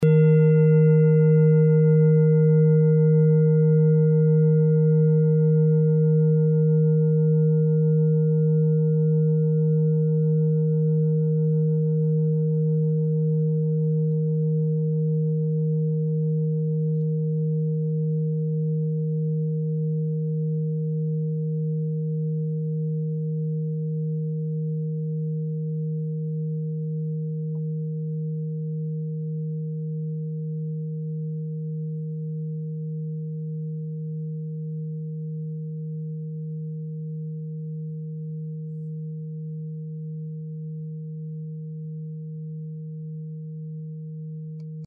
Klangschale Bengalen Nr.40
(Ermittelt mit dem Filzklöppel oder Gummikernschlegel)
klangschale-ladakh-40.mp3